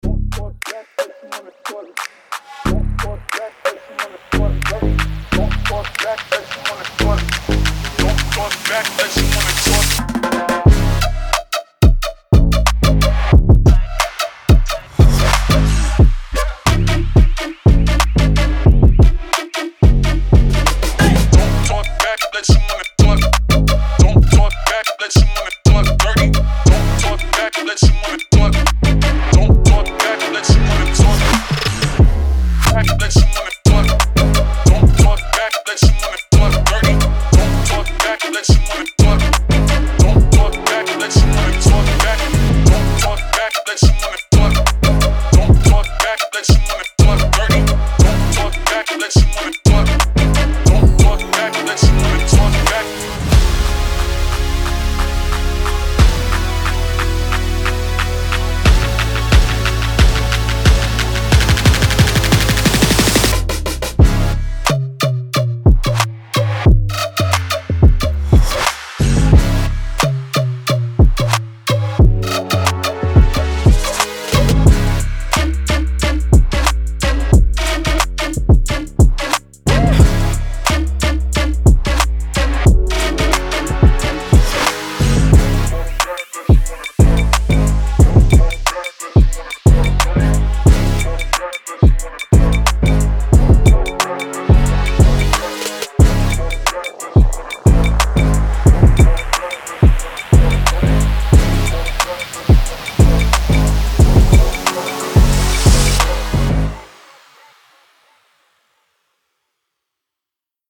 is a soulful ballad with a strong narrative quality
With a gentle melody, emotional lyrics and powerful vocals
Ringtone